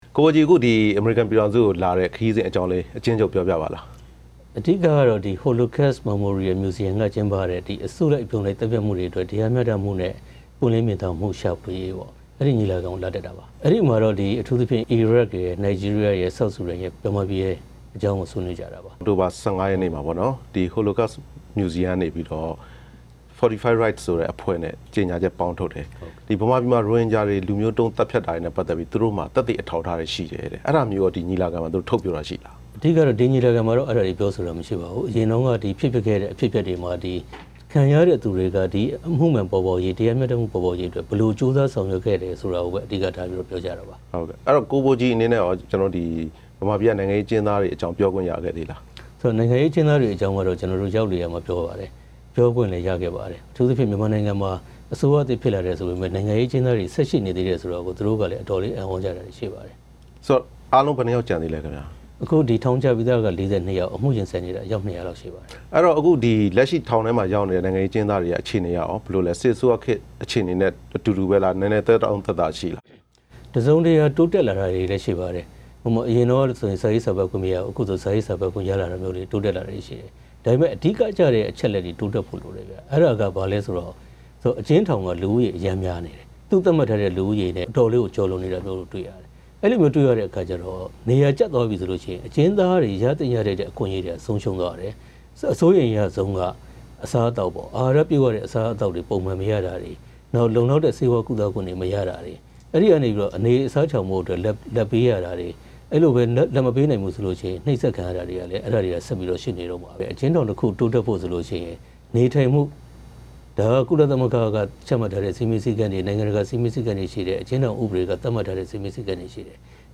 တွေ့ဆုံမေးမြန်းချက်